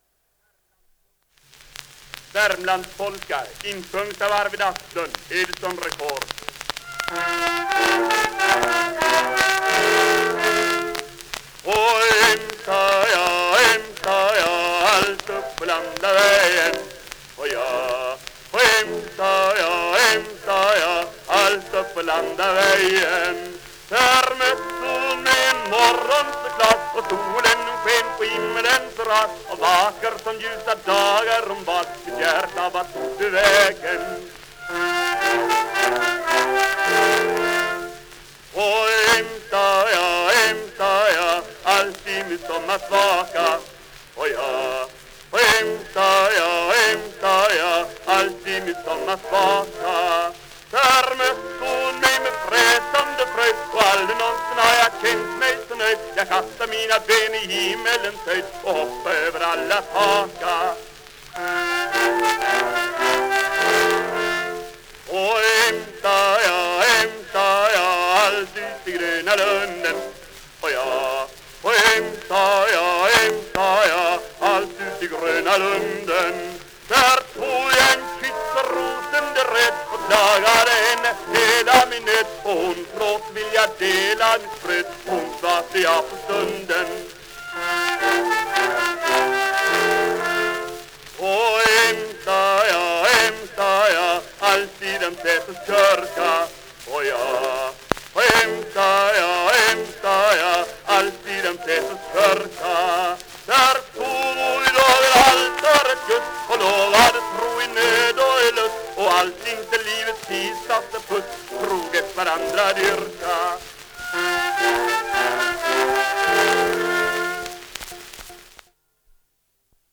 Ljudinspelningar från omkring 1900